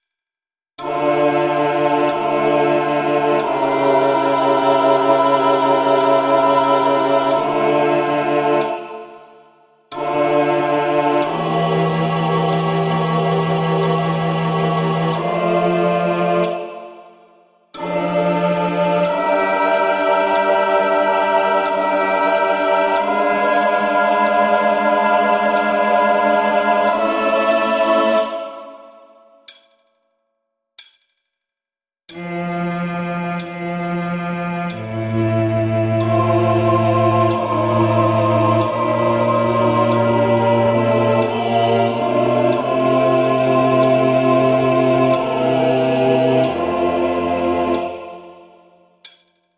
音取り音源
コーラス＋メトロノーム